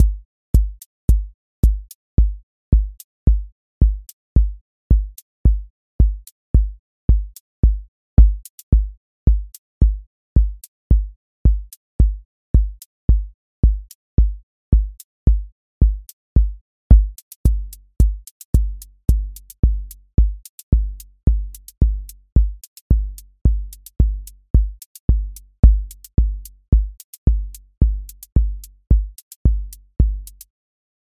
Four Floor Drive QA Listening Test house Template: four_on_floor April 18, 2026 ← Back to all listening tests Audio Four Floor Drive Open MP3 directly Selected Components macro_house_four_on_floor voice_kick_808 voice_hat_rimshot voice_sub_pulse Test Notes What This Test Is Four Floor Drive Selected Components macro_house_four_on_floor voice_kick_808 voice_hat_rimshot voice_sub_pulse